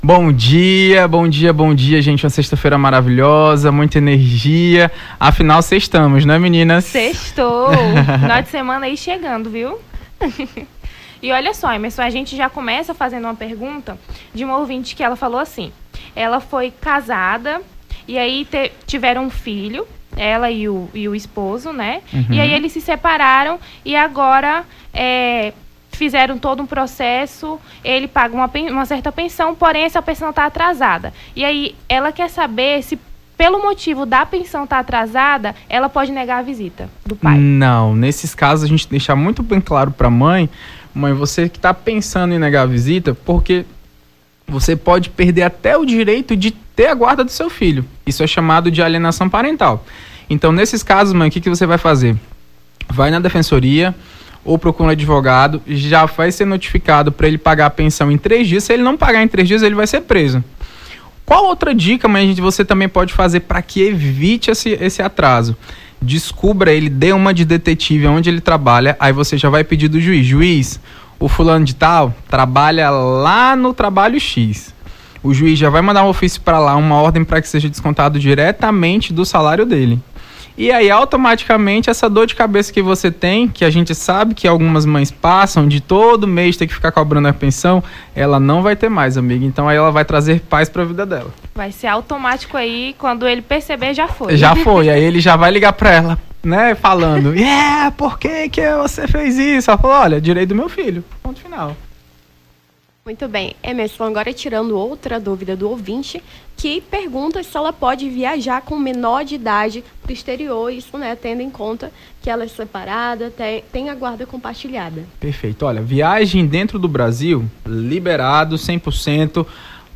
Seus direitos: advogado esclarece dúvidas relacionado ao direito da família
as apresentadoras